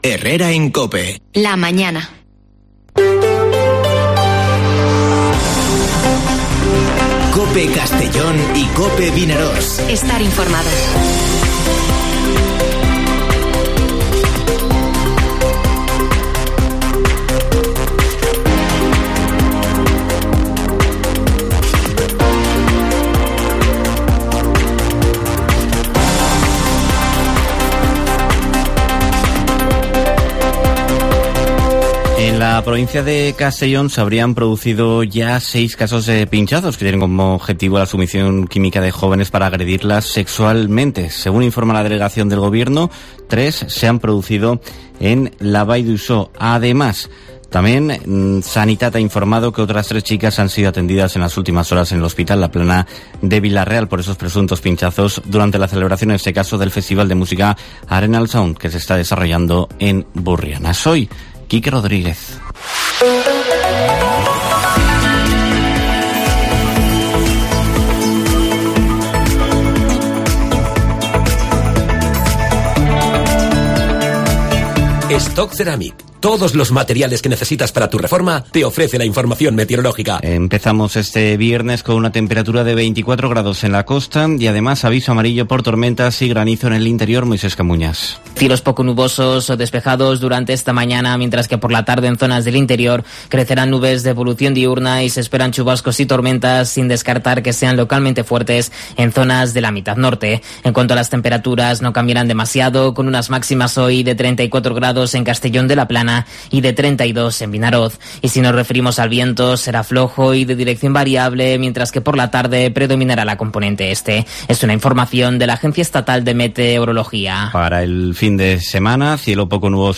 Informativo Herrera en COPE en la provincia de Castellón (05/08/2022)